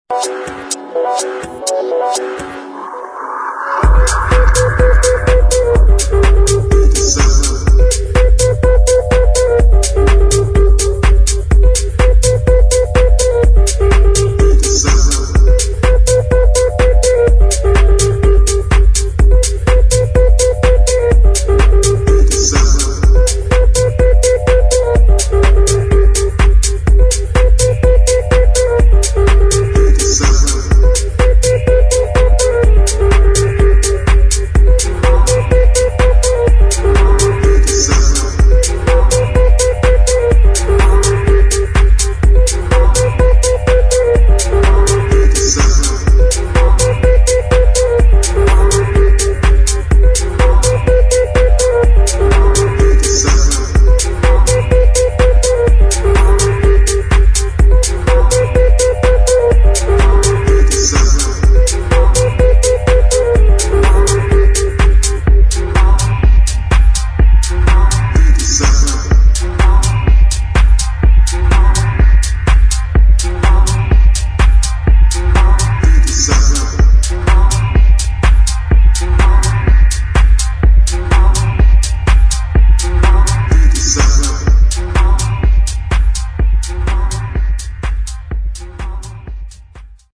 [ DEEP HOUSE / TECH HOUSE ]